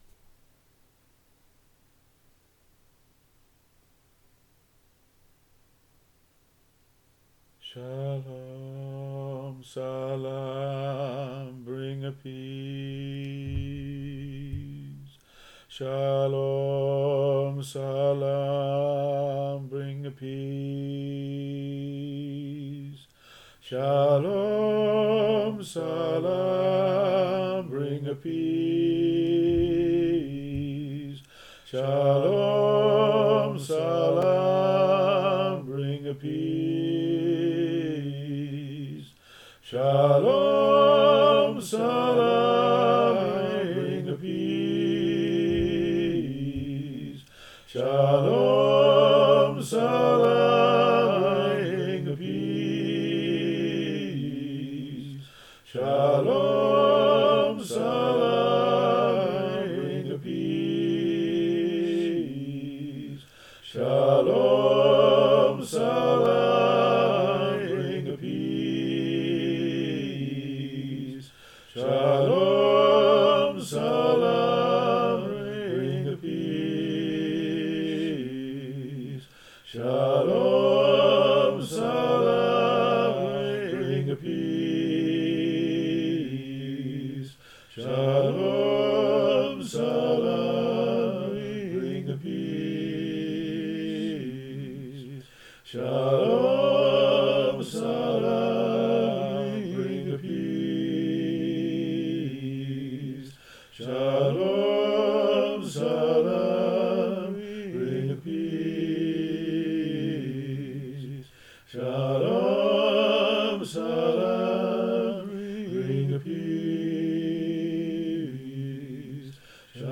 Choir Rounds and multi-part songs